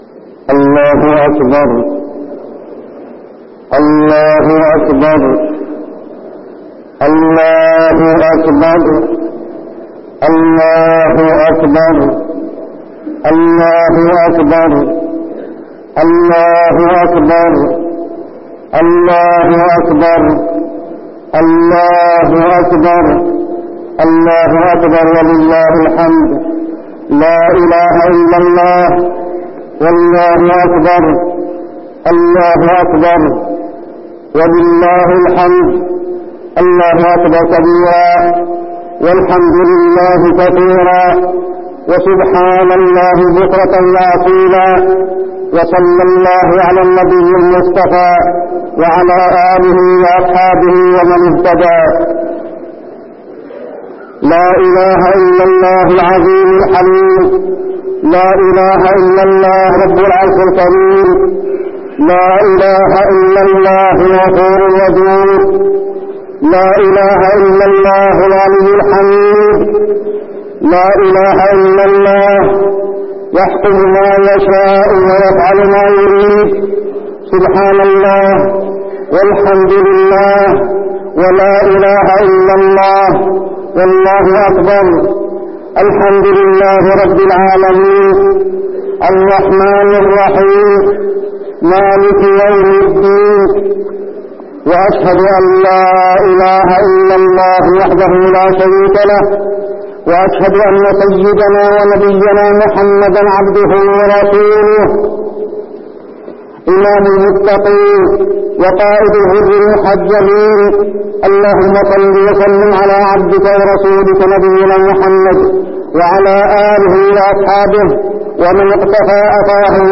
خطبة الاستسقاء - المدينة- الشيخ عبدالله الزاحم
تاريخ النشر ١٤ رجب ١٤٠٩ هـ المكان: المسجد النبوي الشيخ: عبدالله بن محمد الزاحم عبدالله بن محمد الزاحم خطبة الاستسقاء - المدينة- الشيخ عبدالله الزاحم